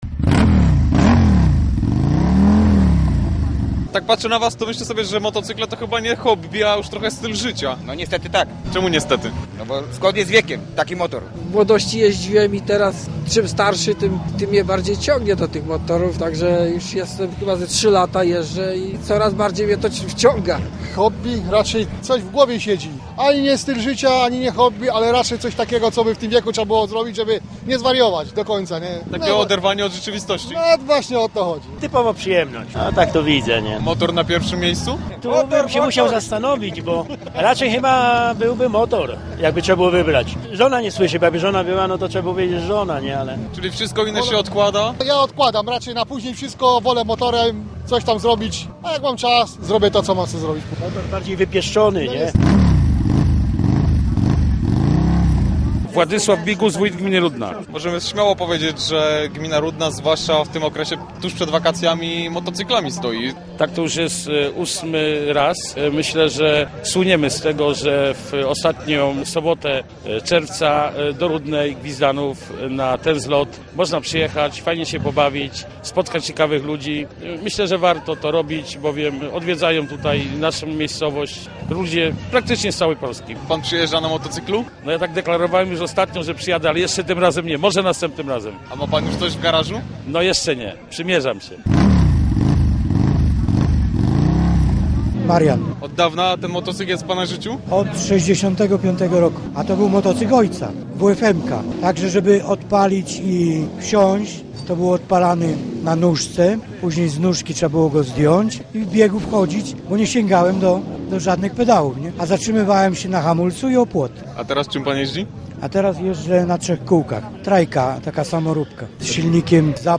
Odbywał się tam VIII Zlot Motocykli, na który przyjechało kilkaset maszyn różnego rodzaju.